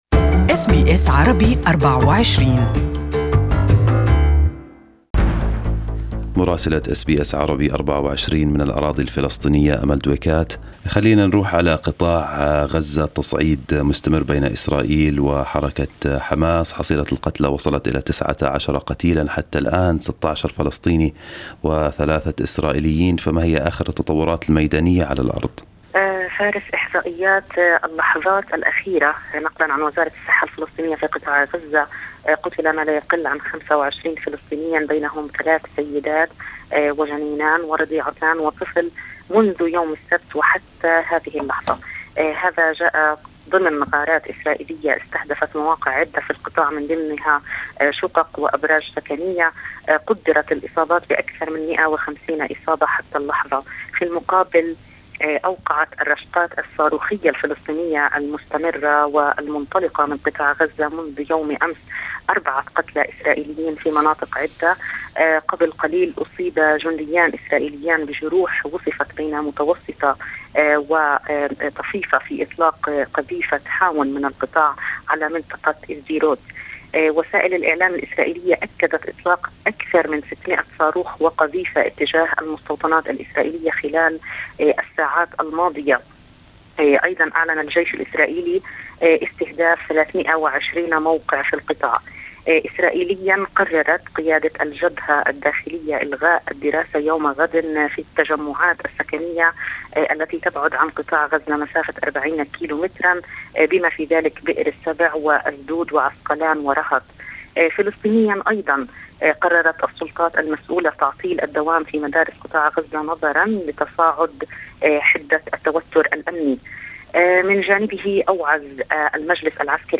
Our correspondent in Ramallah has the details